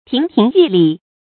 注音：ㄊㄧㄥˊ ㄊㄧㄥˊ ㄧㄩˋ ㄌㄧˋ
讀音讀法：